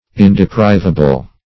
Search Result for " indeprivable" : The Collaborative International Dictionary of English v.0.48: Indeprivable \In`de*priv"a*ble\, a. Incapable of being deprived, or of being taken away.